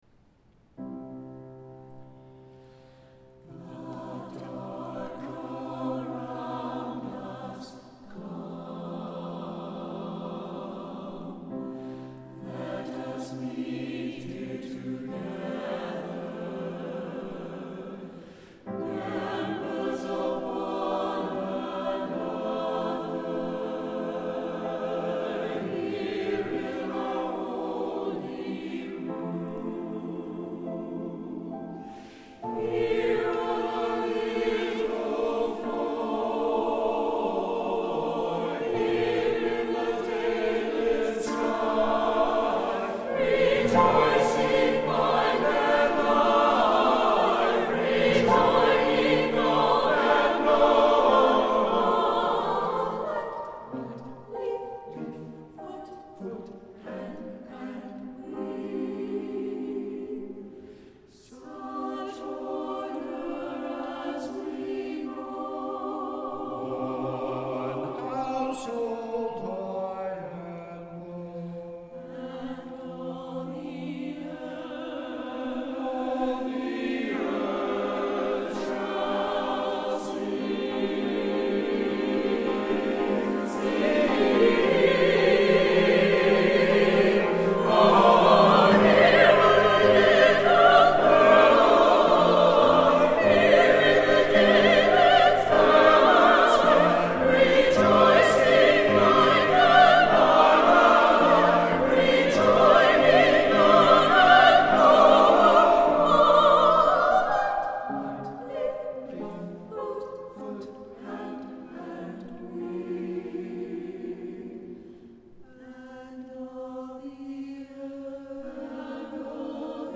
for SATB Chorus, Clarinet, and Piano (2009)
These songs are scored for SATB chorus.